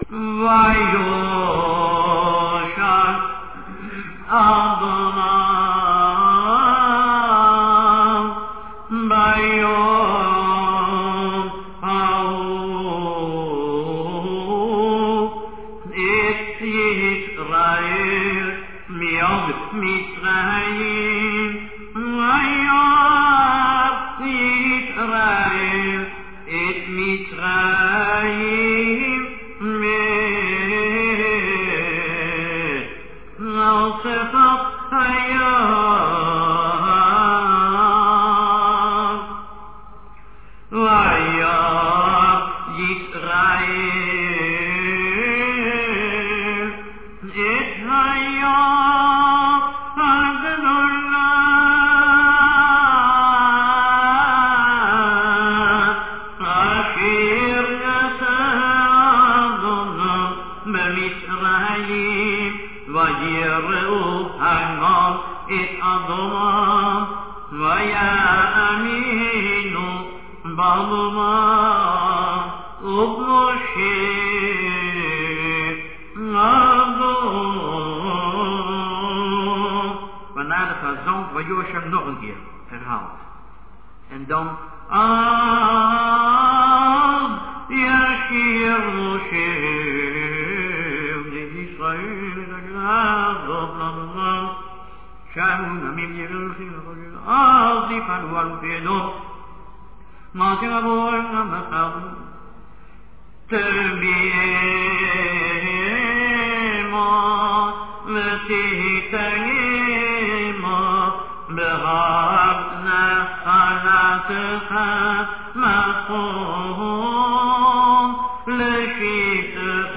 high cantillations. The Chazzan repeats ויושע and תבאמו. After כי בא he repeats the lat verse ויאמר on the high melody.
NN3-shira-Beshalach.mp3